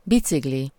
Ääntäminen
IPA : /ˈbaɪsɪkəl/